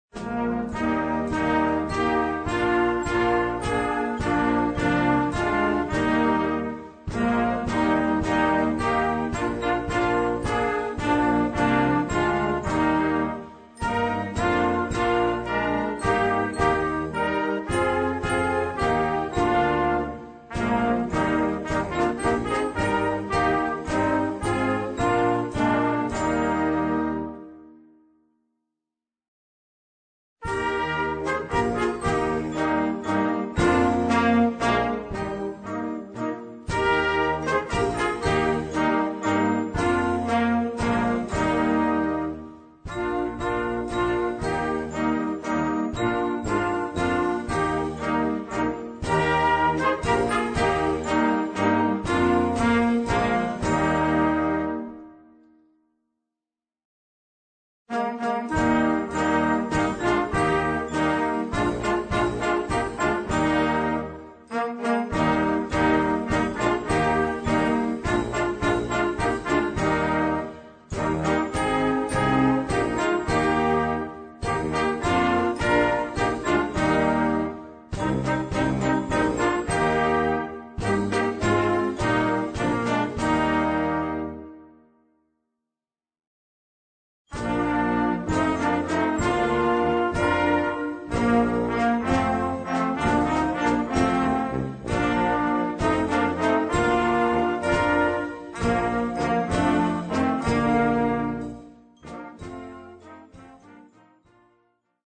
brass band
Partitions pour ensemble flexible, 4-voix + percussion.